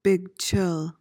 PRONUNCIATION: (shuh-LAK) MEANING: noun: A resin secreted by the lac insect and purified for use in varnishes, paints, inks, sealing waxes, phonograph records, etc. A phonograph record, especially a 78 rpm.